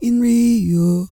E-CROON 3009.wav